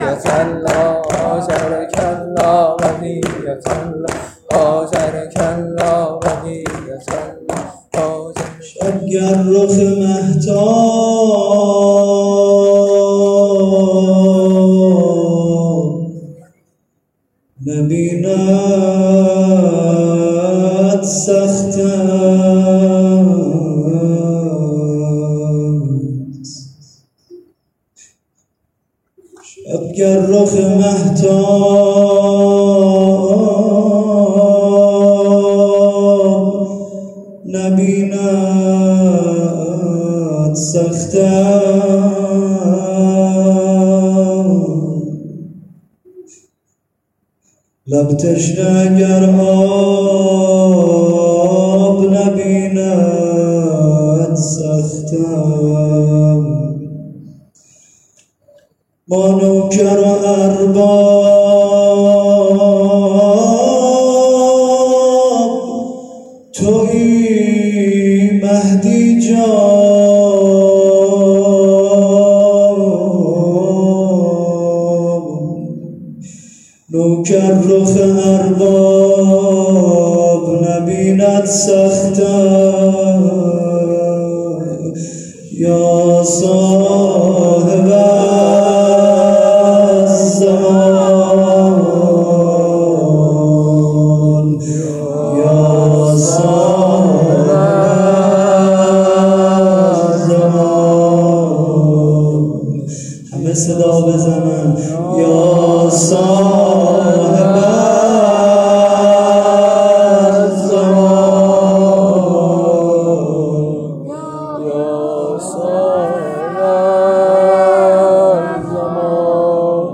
مناجات پایانی
شب پیشواز محرم 99